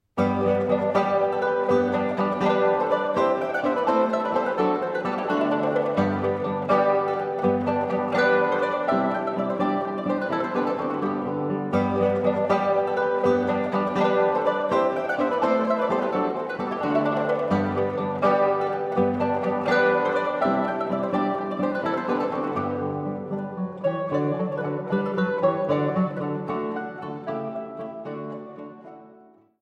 Laute & Mandoline